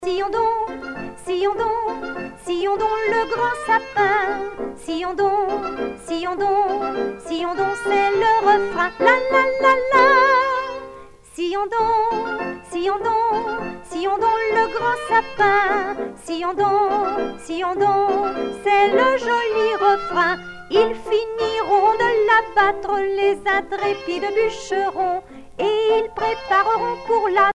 danse : soyotte
Pièce musicale éditée